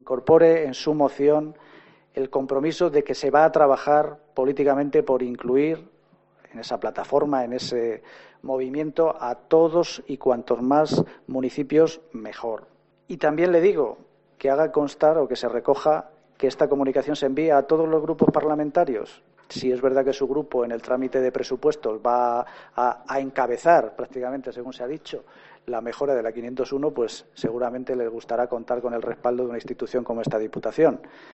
Juan Pablo Martín, portavoz del equipo de Gobierno